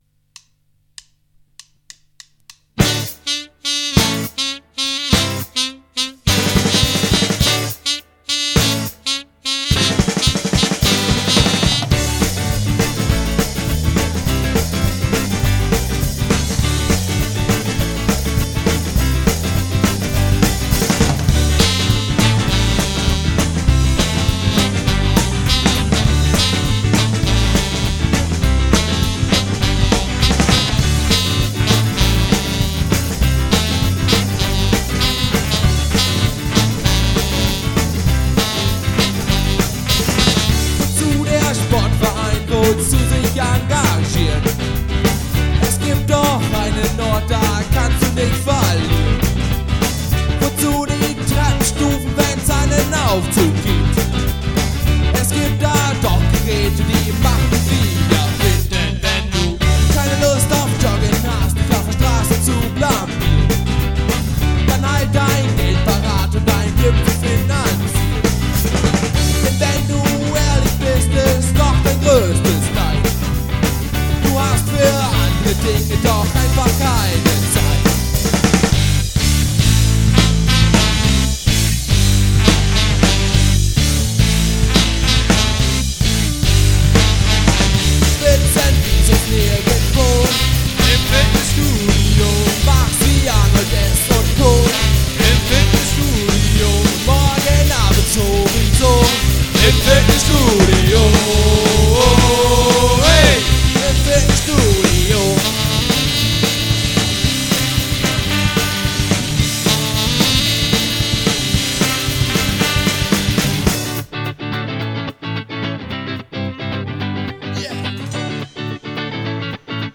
Ska-Rock
Bass
Posaune
Sax